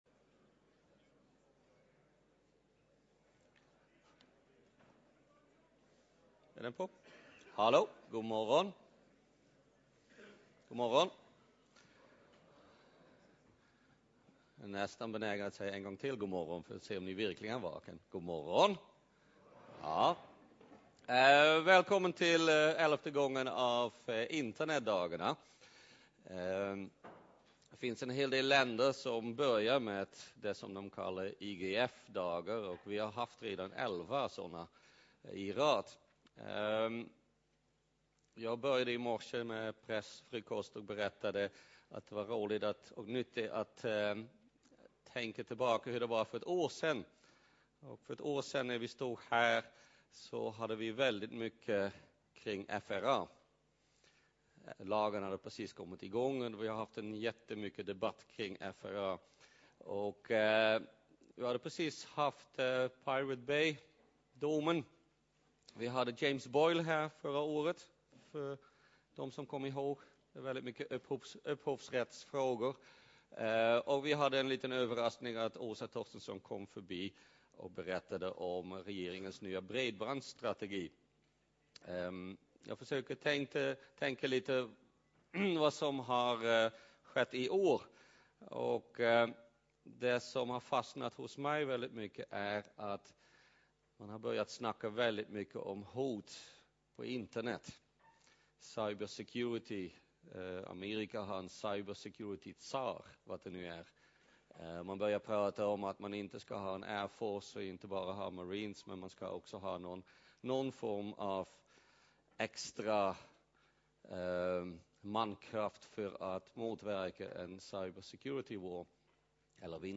Keynote dag 1 (LIVESÄNDS)
Plats: Kongresshall A